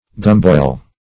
Search Result for " gumboil" : Wordnet 3.0 NOUN (1) 1. a boil or abscess on the gums ; The Collaborative International Dictionary of English v.0.48: Gumboil \Gum"boil\ (g[u^]m"boil), n. (Med.)